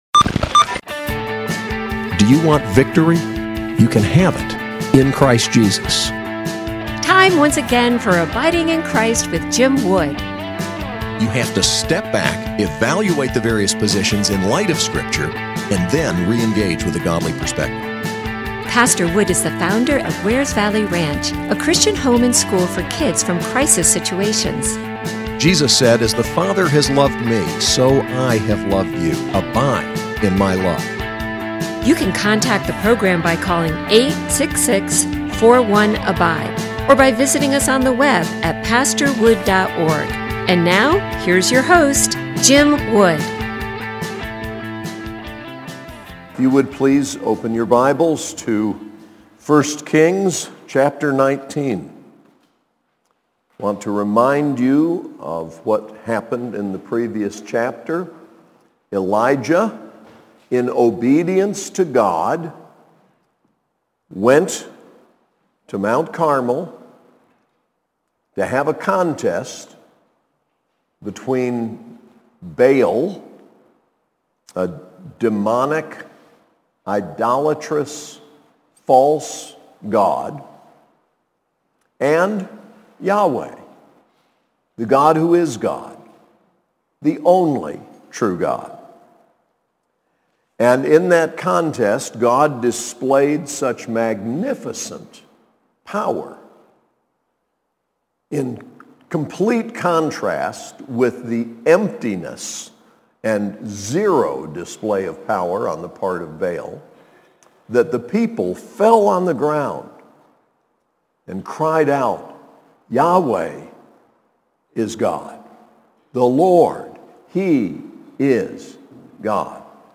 SAS Chapel: 1 Kings 19
Preacher